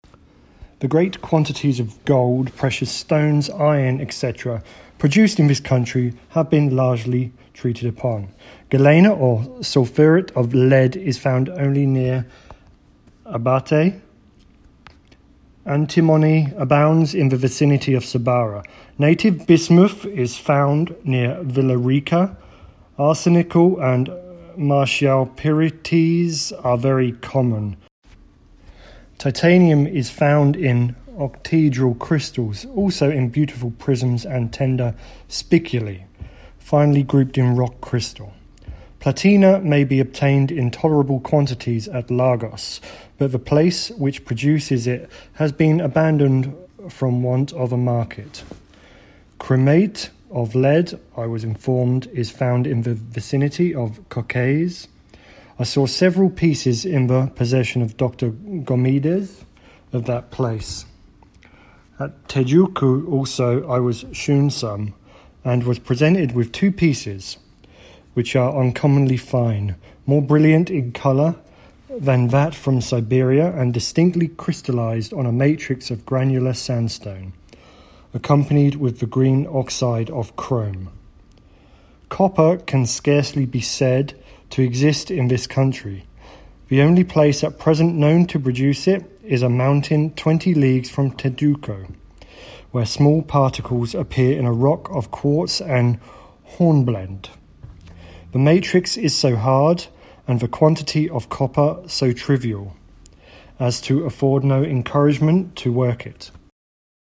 TRECHO retirado do livro Travels in the Interior of Brazil, de John Mawe, xxxx p. 269. Áudio narrado em Inglês.